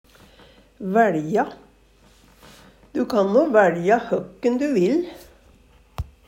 væLja - Numedalsmål (en-US)
DIALEKTORD PÅ NORMERT NORSK væLja velge mellom fleire Infinitiv Presens Preteritum Perfektum væLja væL vaLde vaLt Eksempel på bruk Du kan væLja høkken du vil.